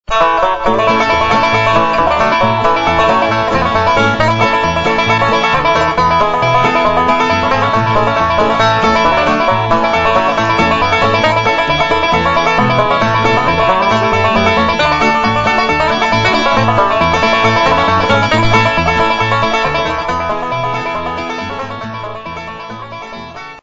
5-String Banjo .mp3 Samples
5-String Banjo Samples - Intermediate Level